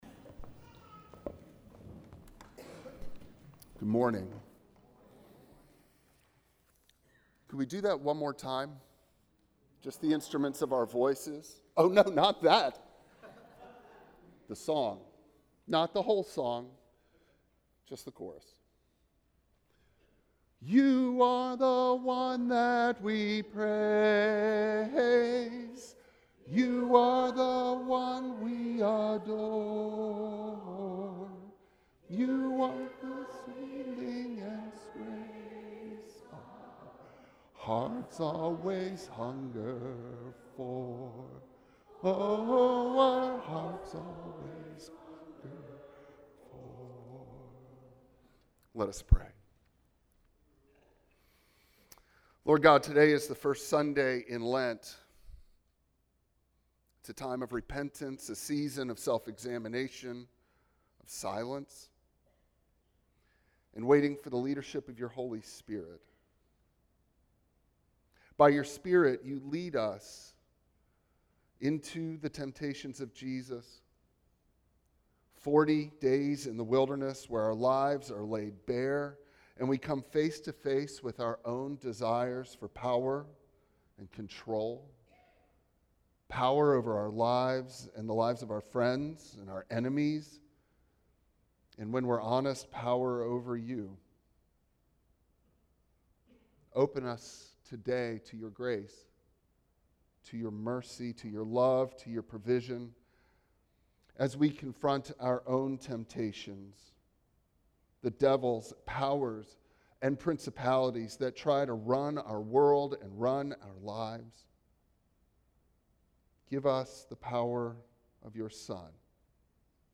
Service Type: Traditional Service